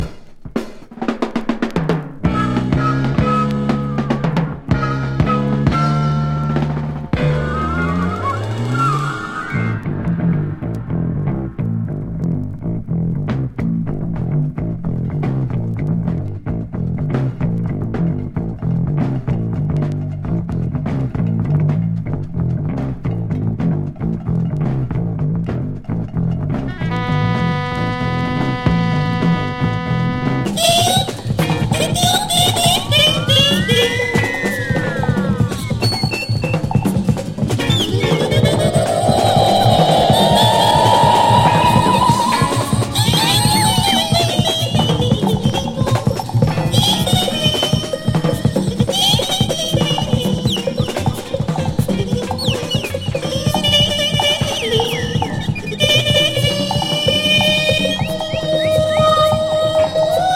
ポーランドの鍵盤奏者率いるジャズロック・グループ’75年作。